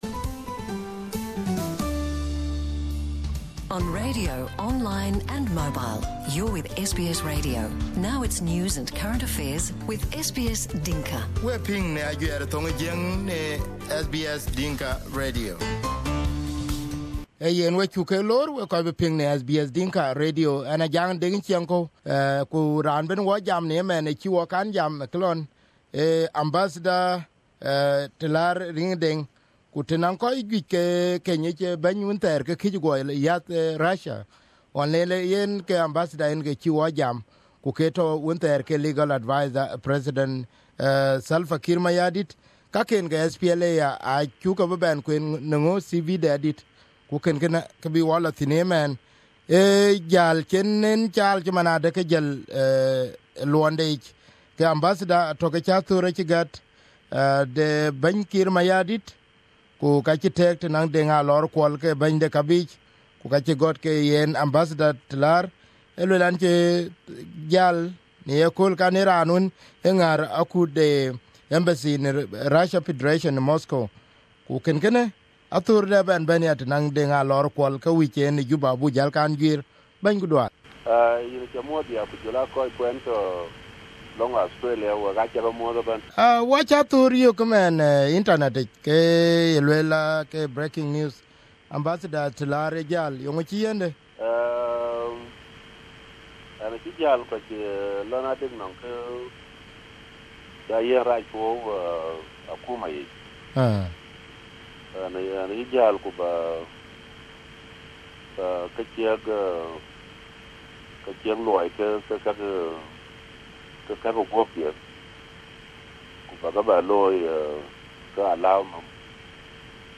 Telar said the situation in Russia that he was working in as ambassador was not conducive and he needed to spend more time with his family. This interview was conducted on the 25/01/2018 while he was in Nairobi.